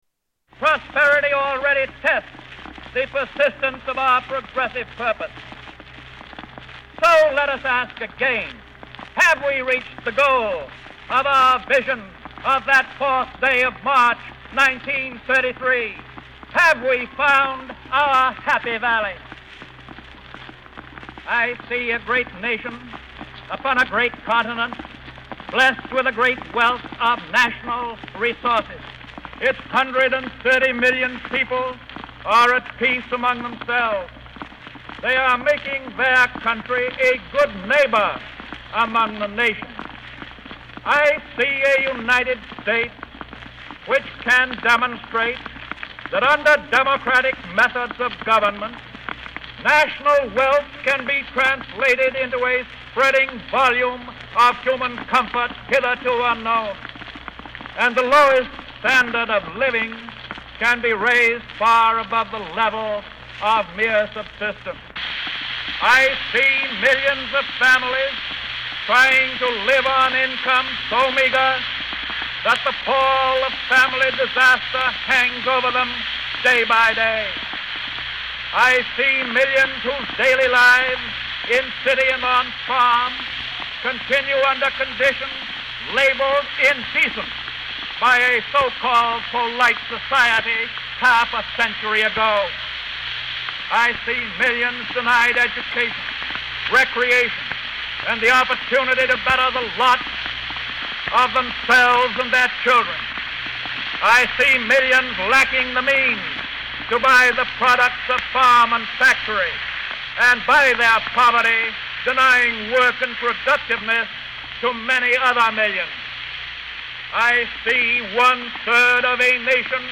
Tags: Famous Inaguration clips Inaguration Inaguration speech President Obama